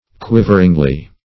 Search Result for " quiveringly" : The Collaborative International Dictionary of English v.0.48: Quiveringly \Quiv"er*ing*ly\ (kw[i^]v"[~e]r*[i^]ng*l[y^]), adv.
quiveringly.mp3